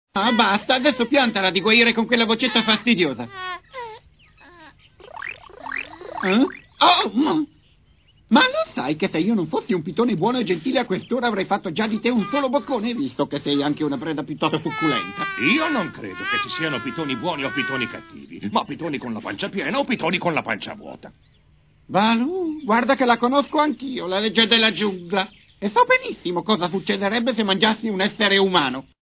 nel cartone animato "Il libro della giungla", in cui doppia Kaa.